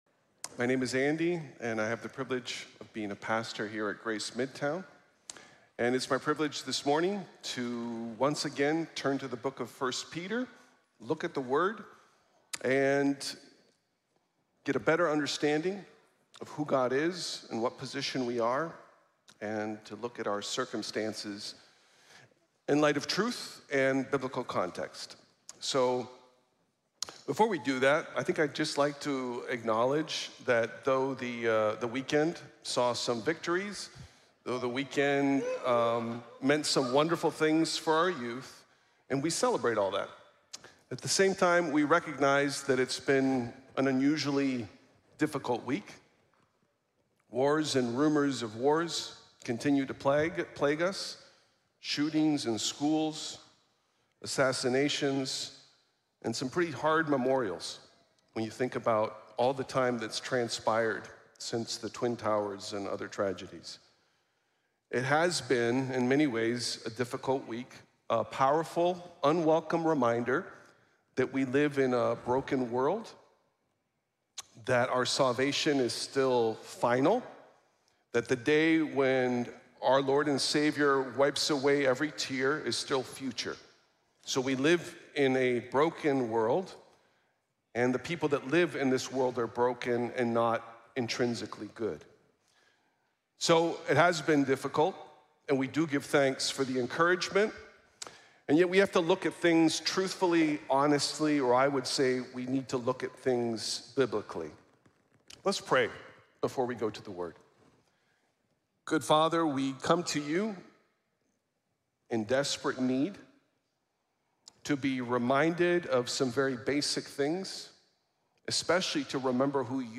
El Buen Dolor | Sermón | Iglesia Bíblica de la Gracia